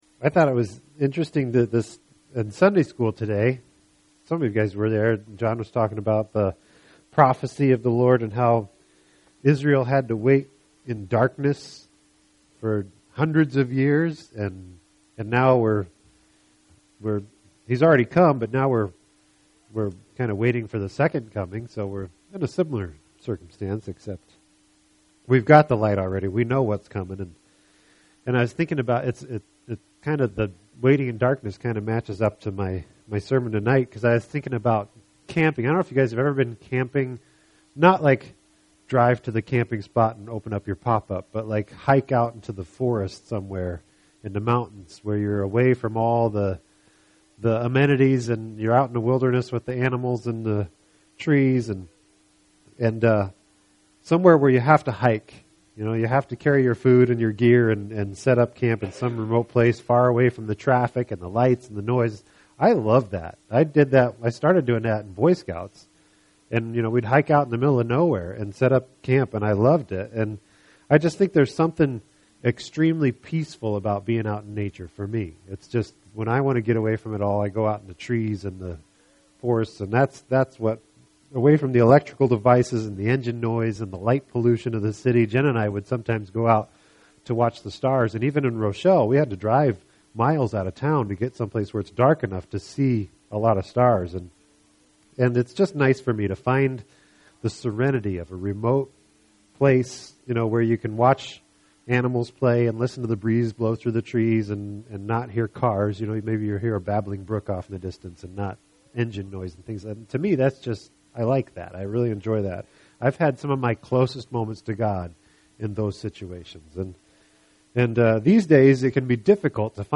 This entry was posted on Sunday, November 4th, 2012 at 1:23 am and is filed under Sermons.